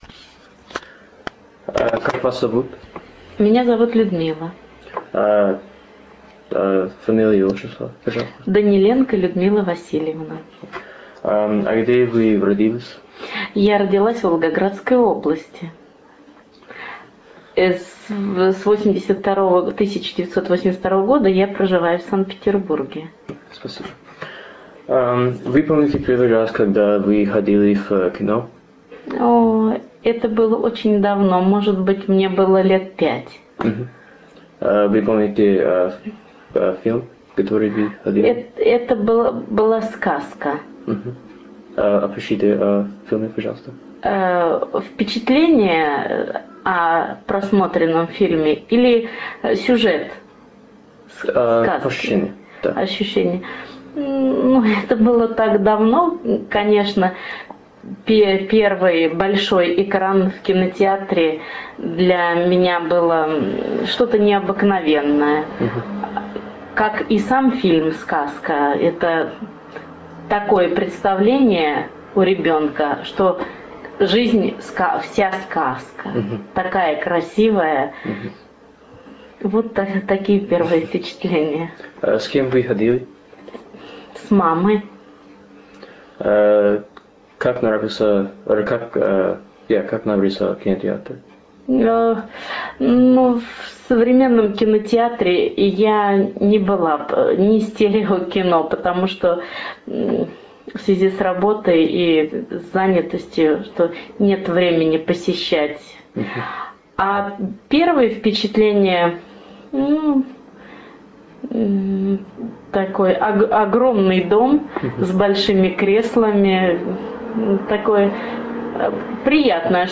Audio of the Interview